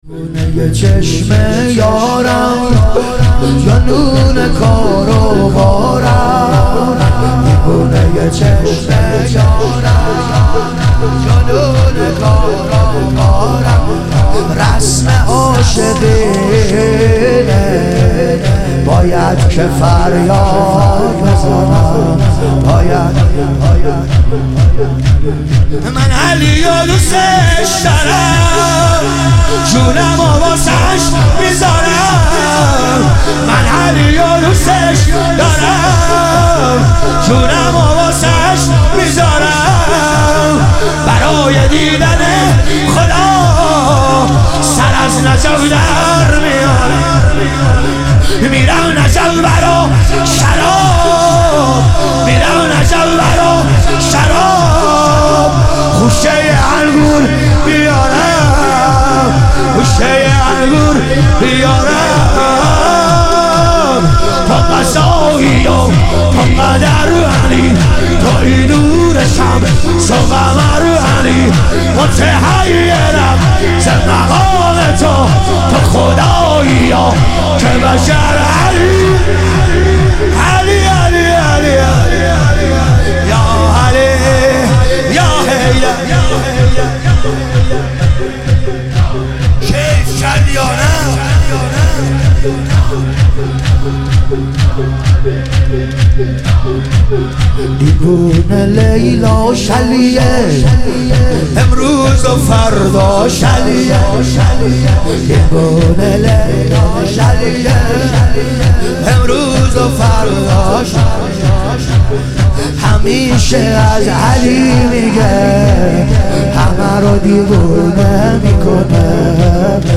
شهادت امام حسن مجتبی علیه السلام - شور